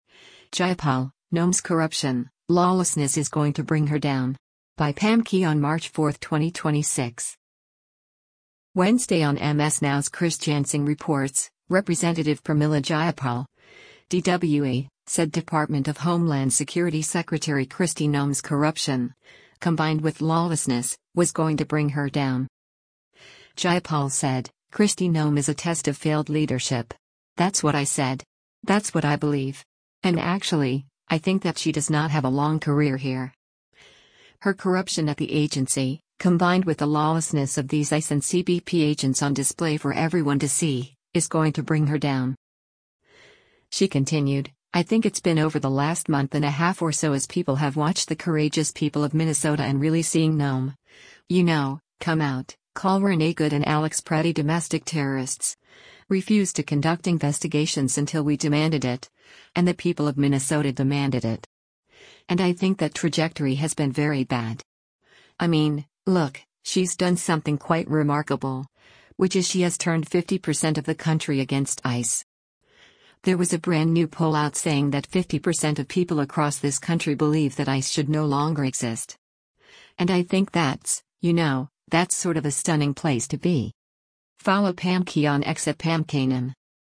Wednesday on MS NOW’s “Chris Jansing Reports,” Rep. Pramila Jayapal (D-WA) said Department of Homeland Security Secretary Kristi Noem’s corruption, combined with lawlessness, was “going to bring her down.”